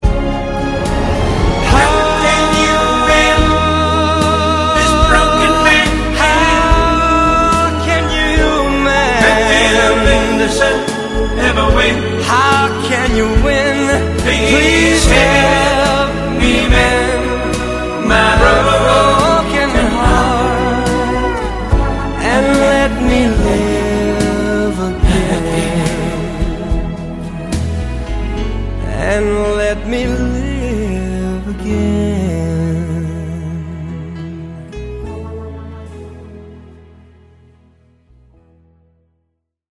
con tanto di “vibratoni...